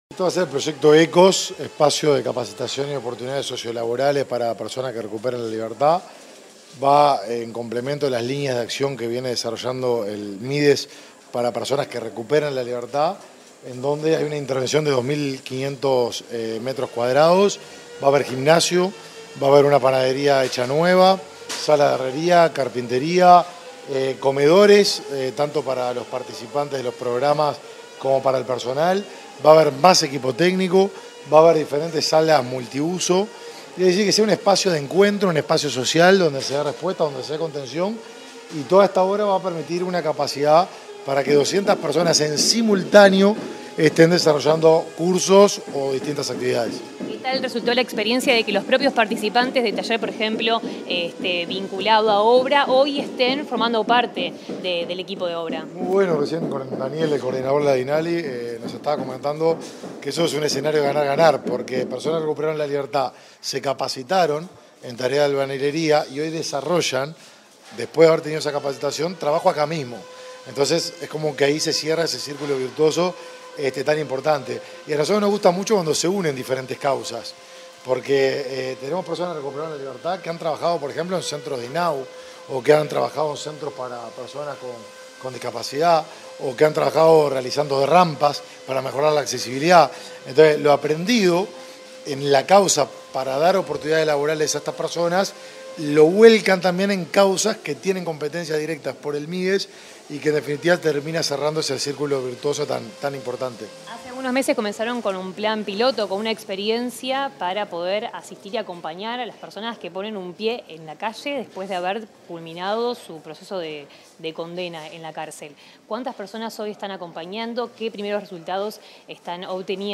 Declaraciones del ministro de Desarrollo Social, Martín Lema
Declaraciones del ministro de Desarrollo Social, Martín Lema 01/02/2024 Compartir Facebook X Copiar enlace WhatsApp LinkedIn Tras la visita de las obras del Espacio de Capacitación y Oportunidades Sociolaborales de la Dirección Nacional de Apoyo al Liberado, este 1 de febrero, el ministro de Desarrollo Social, Martín Lema, realizó declaraciones a la prensa.